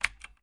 电脑键盘 " 电脑键盘 单键 3型
描述：电脑键盘输入声音个别击键声＃3
Tag: 打字机 击键 打字